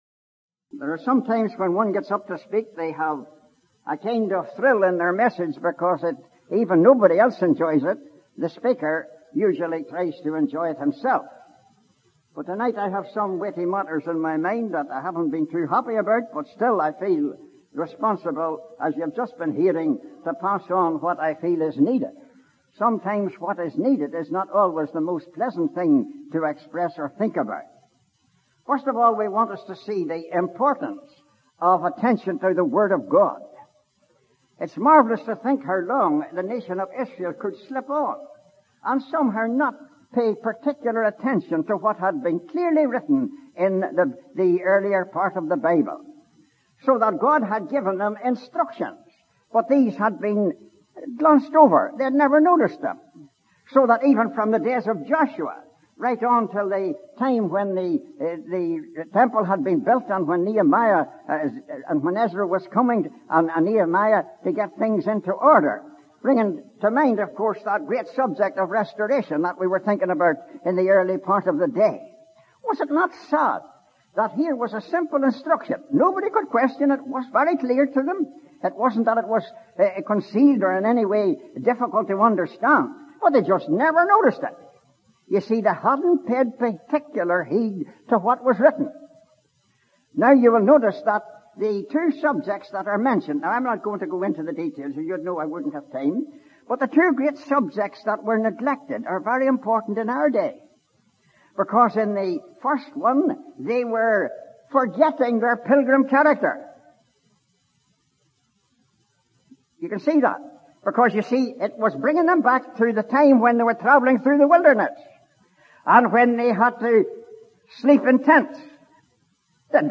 (Recorded at the Belfast Easter Conference, April 1999)
Historical Ministry Sermons